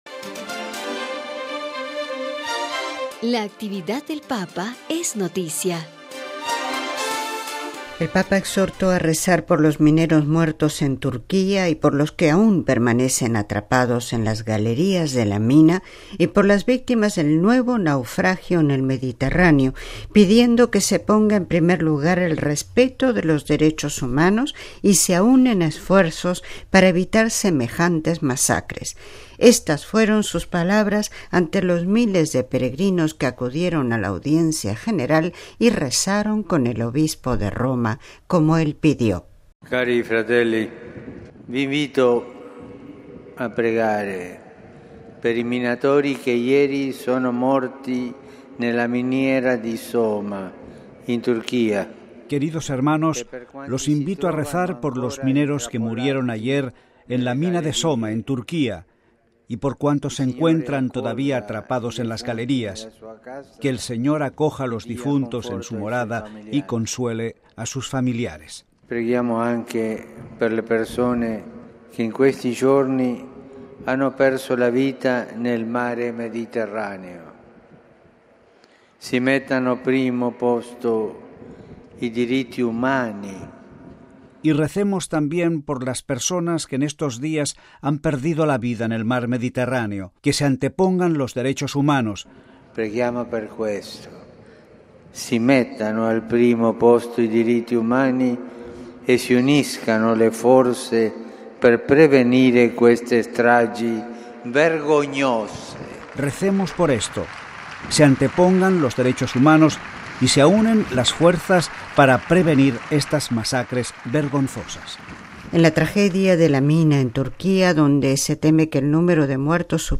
MP3 Pidiendo que se anteponga siempre el respeto de los derechos humanos y se aúnen esfuerzos para evitar semejantes masacres, el Papa Francisco, exhortó a rezar por los mineros muertos en Turquía y por los que aún permanecen atrapados en las galerías de la mina y por las víctimas del nuevo naufragio en el Mediterráneo. Éstas fueron sus palabras antes los miles de peregrinos que acudieron a la audiencia general y rezaron en el Obispo de Roma, como él pidió: